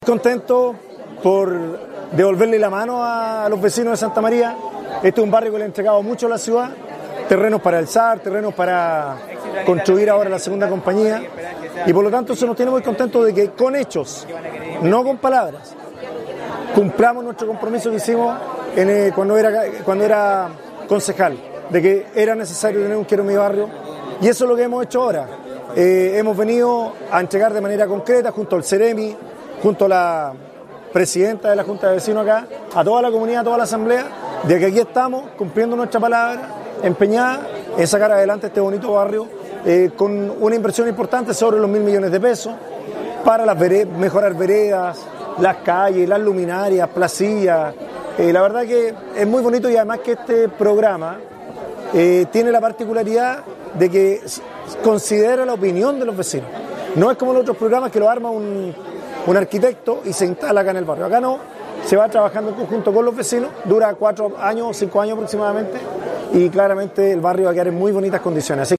Audio Alcalde Fabian Perez
FABIAN-PEREZ-ALCALDE-DE-CONSTT_01.mp3